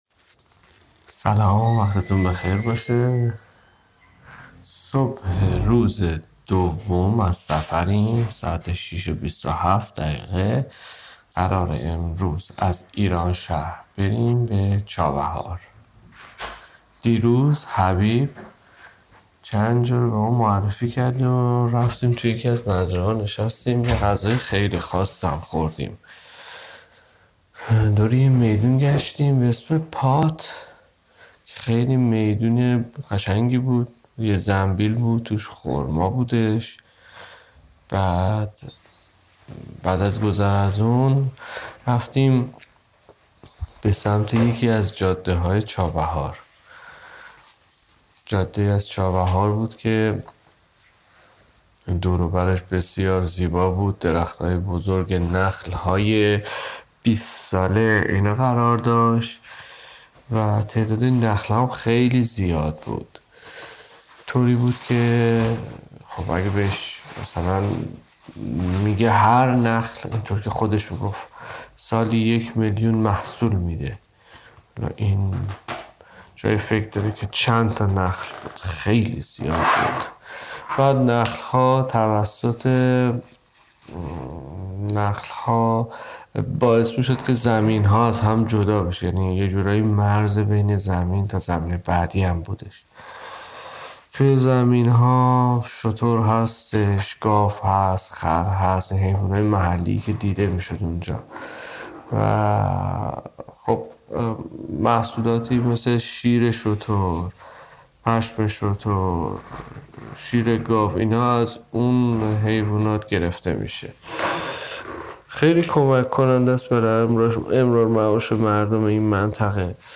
لایوکست ( لایو اجرا شده و ادیت نشده )